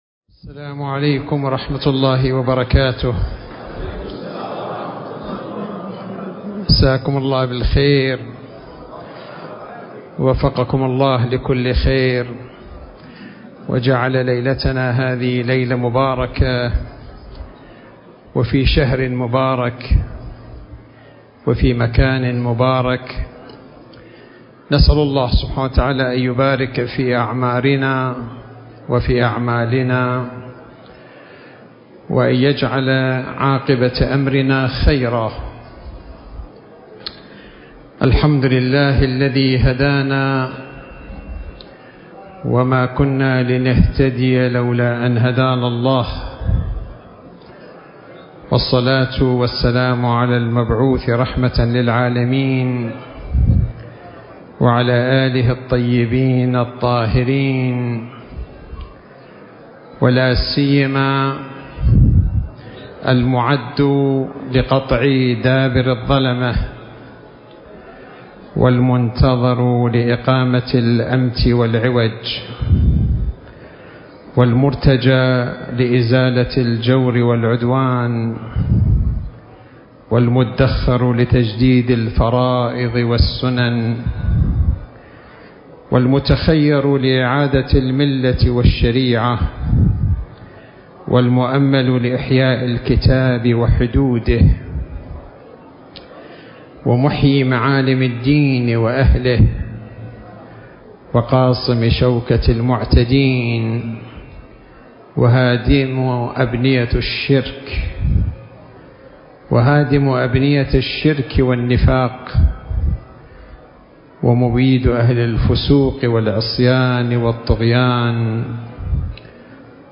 الملتقى الثقافي لمؤسسة التضامن الولائي الثقافية تحت عنوان مسؤولية الأمة في عصر الغيبة الكبرى